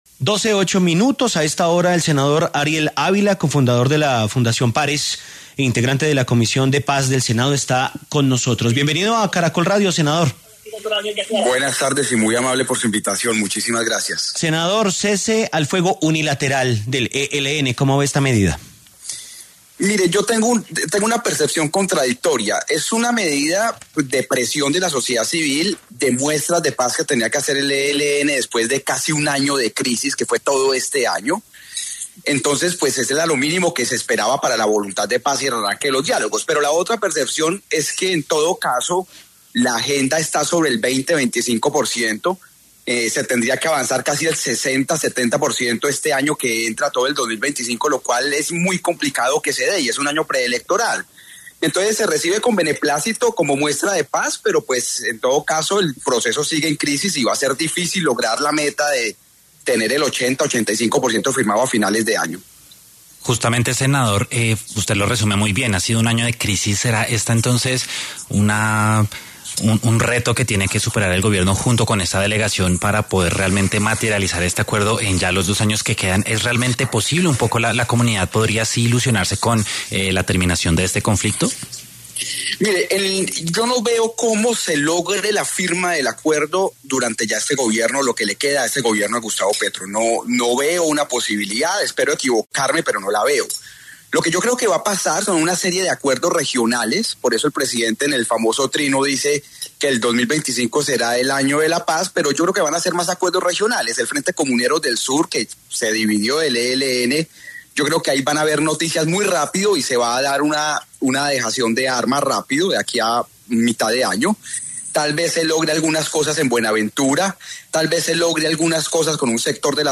En diálogo con el Noticiero del Mediodía de Caracol Radio, el senador del Partido Verde Ariel Ávila, exsubdirector de la Fundación Paz y Reconciliación y actual vicepresidente de la Comisión de Paz del Senado, habló sobre el cese al fuego unilateral durante las fiestas decembrinas que anunció el ELN.